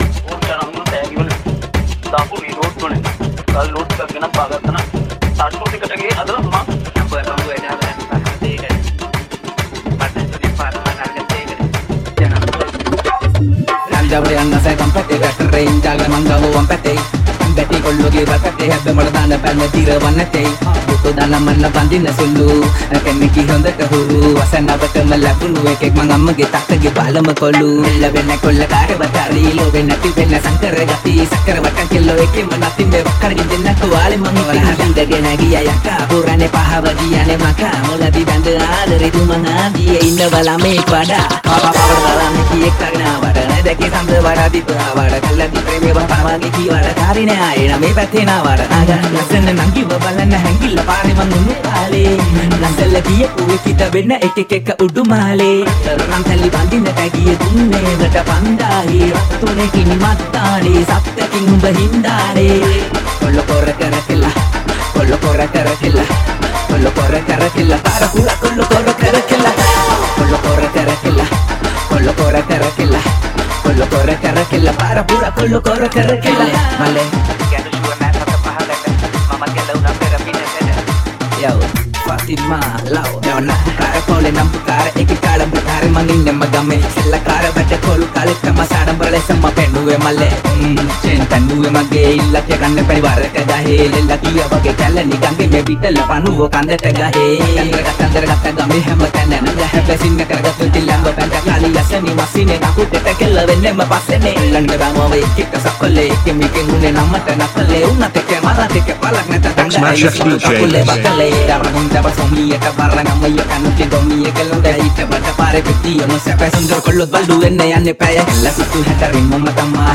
High quality Sri Lankan remix MP3 (3.8).